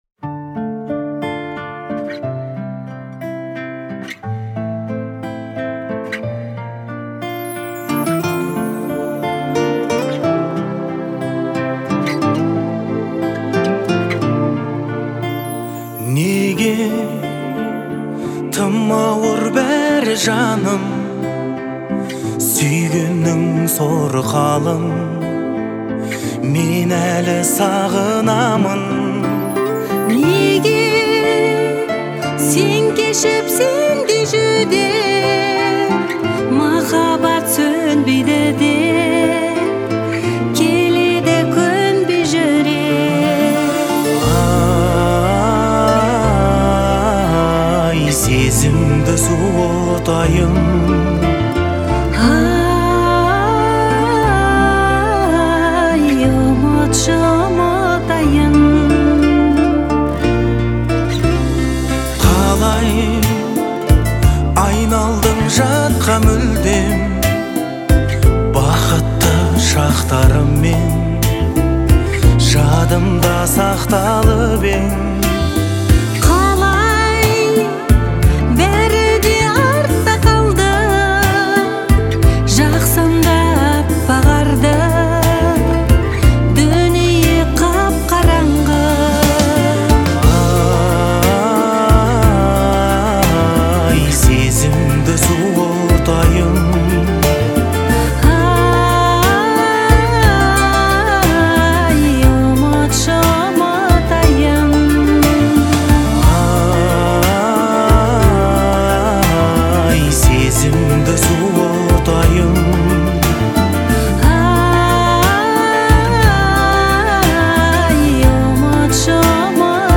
современный казахский поп с элементами этнической музыки